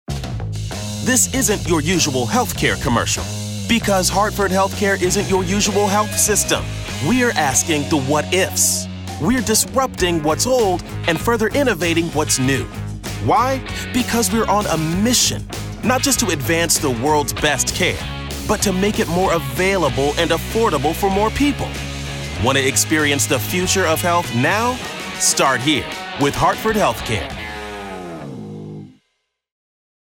DOWNLOAD Brand Evolution Trailer Video DOWNLOAD :30 TV Commercial DOWNLOAD :30 Radio Spot DOWNLOAD Brand Evolution Presentation DOWNLOAD The Story © 2026 Hartford HealthCare.